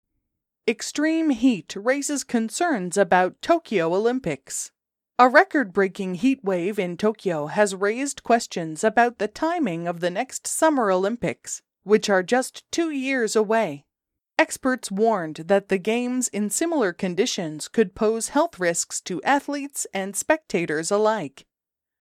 ナチュラルスピードで話される英語は、子音と母音の音がつながったり、子音が脱落して聞こえなくなる現象がよく起こります
※ここでは標準的なアメリカ英語のリスニングを想定しています。
ディクテーション課題の英文